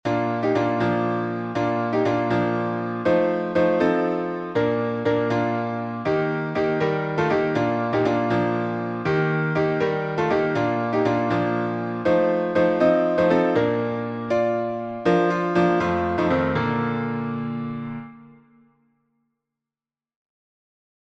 Key signature: B flat major (2 flats) Time signature: 6/8